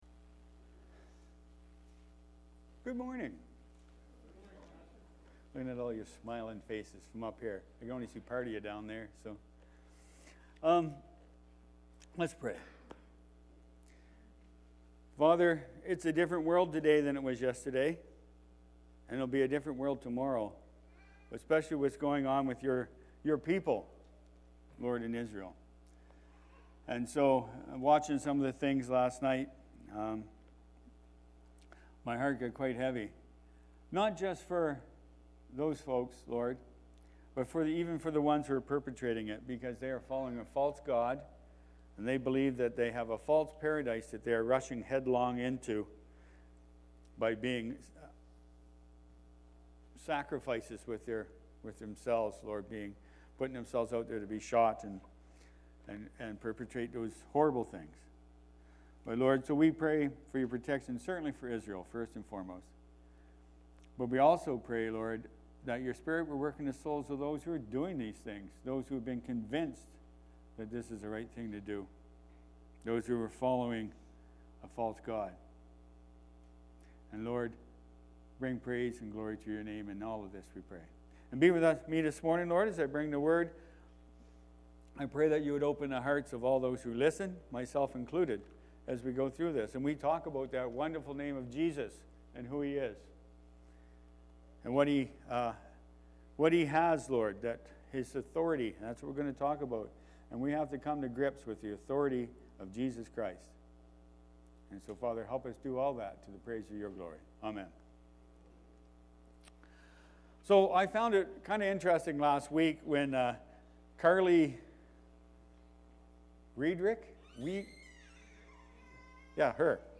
October-8th-2023-sermon-audio.mp3